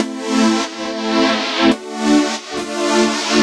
Index of /musicradar/french-house-chillout-samples/140bpm/Instruments
FHC_Pad B_140-A.wav